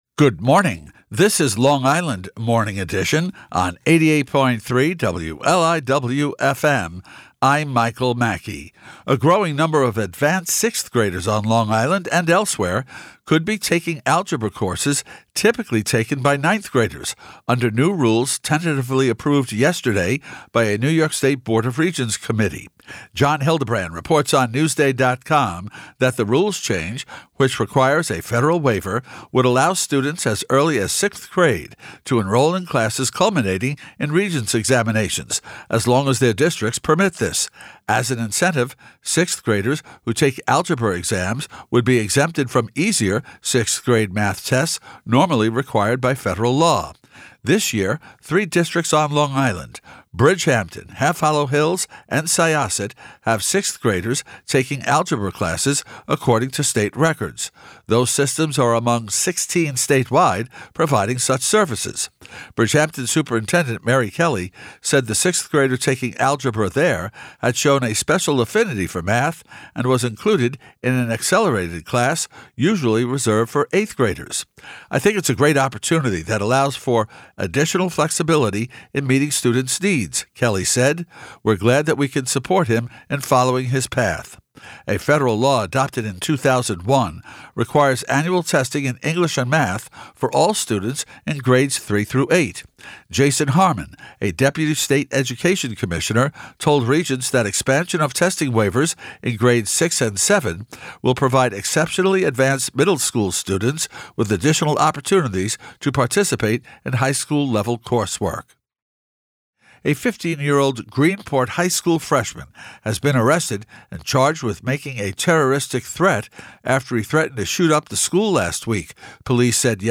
newscast.mp3